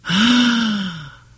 Man Gasp 6 Sound Effect Free Download
Man Gasp 6